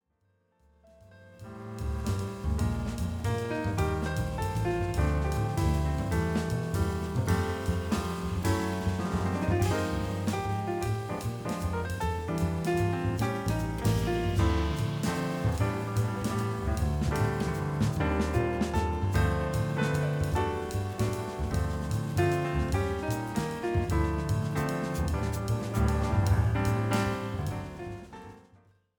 Recorded principally at Van Gelder Studios
Piano, Keyboards Composer
Bass
Drums
Violin
Vocals
Harp & Percussion